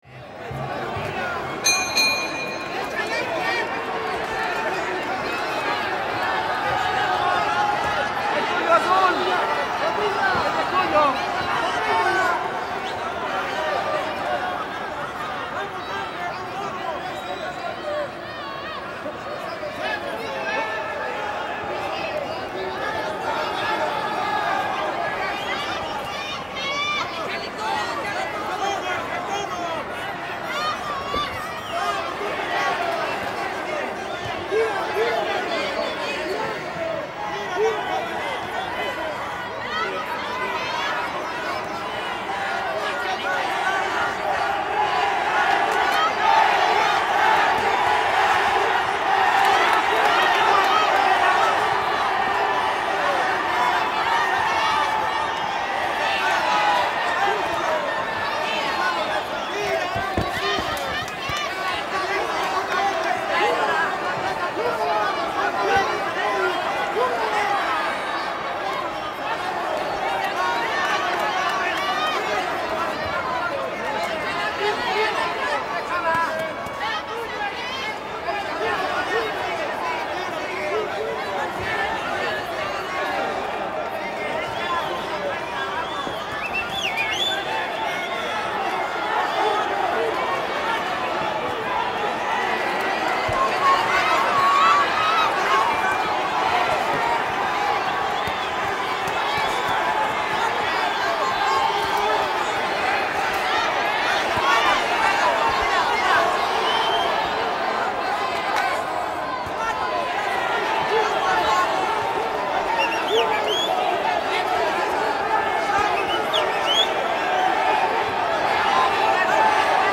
Звуки бокса
Звук профессионального бокса один раунд